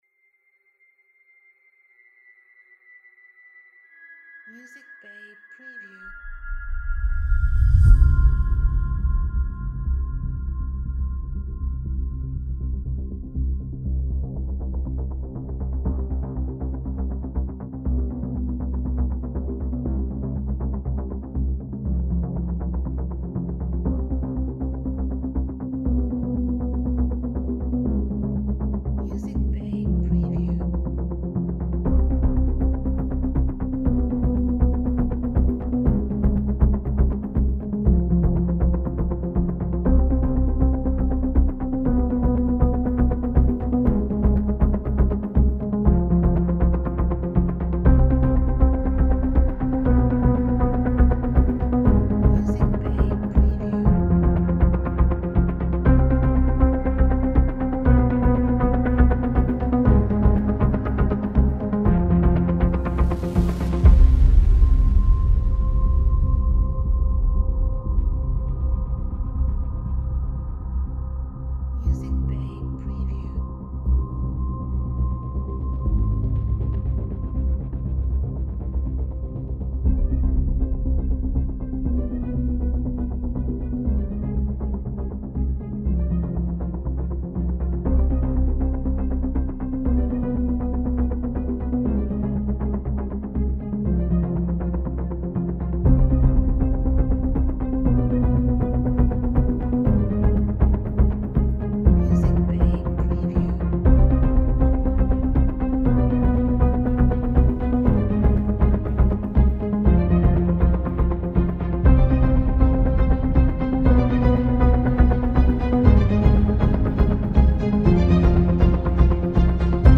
Cinematic music backgrounds